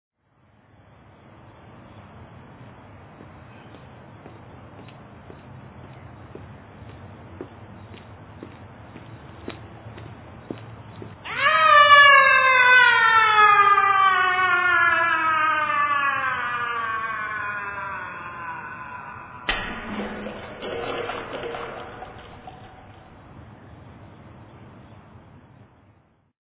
We have interpreted this as a "goons-like" sound effect, and added a splash - "He's fallen in the water!".
manhole.mp3